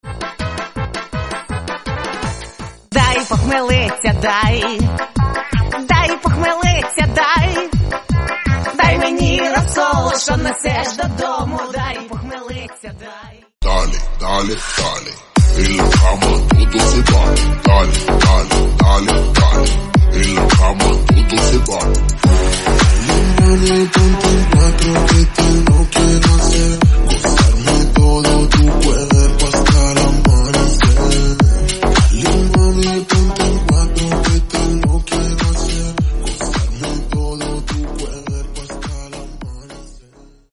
• Качество: 64, Stereo
мужской голос
качающие